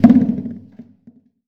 TC2 Perc10.wav